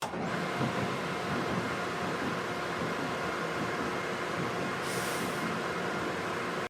SFX
Roller Coaster Power Up.mp3